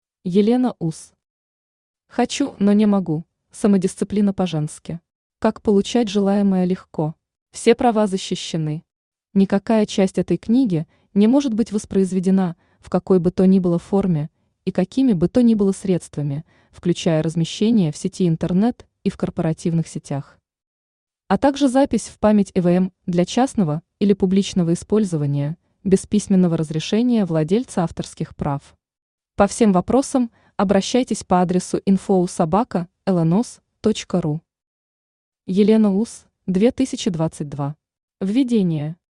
Аудиокнига Хочу, но не могу: самодисциплина по-женски. Как получать желаемое легко | Библиотека аудиокниг
Aудиокнига Хочу, но не могу: самодисциплина по-женски. Как получать желаемое легко Автор Елена Уз Читает аудиокнигу Авточтец ЛитРес.